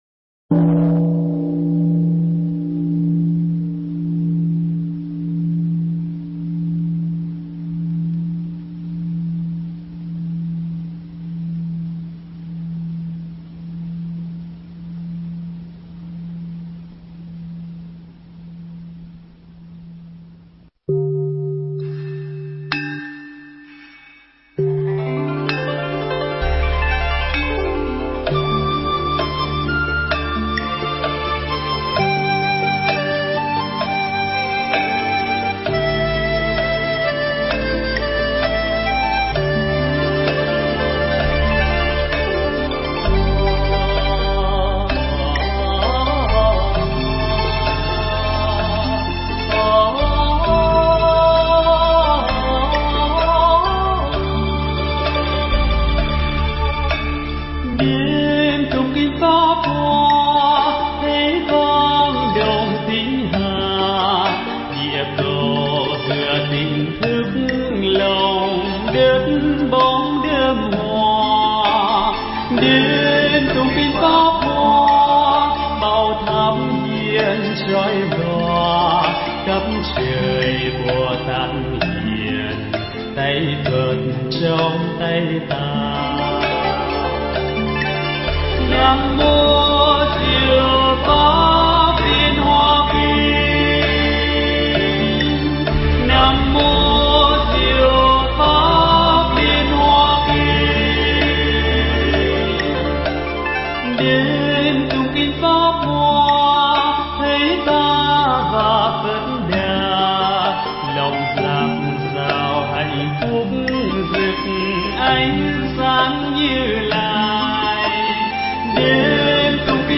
Nghe Mp3 thuyết pháp Hạnh Nguyện Pháp Hoa